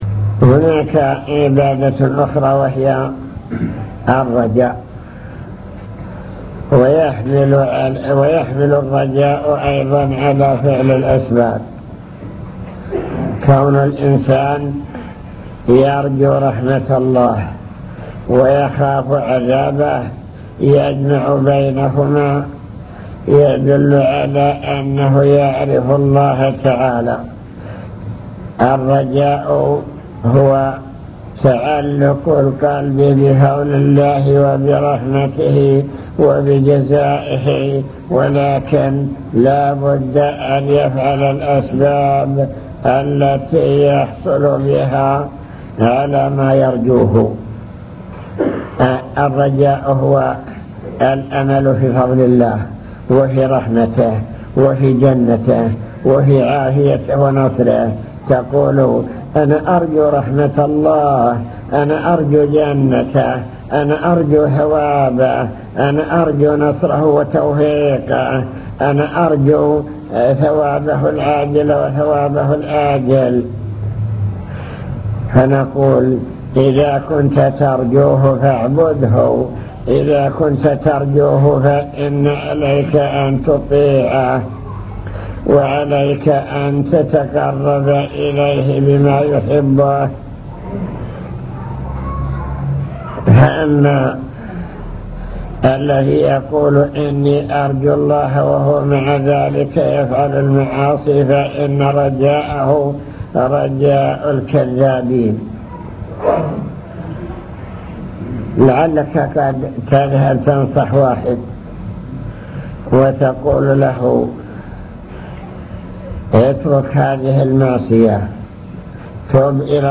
المكتبة الصوتية  تسجيلات - محاضرات ودروس  نوافل العبادات وأنواعها العبادات القولية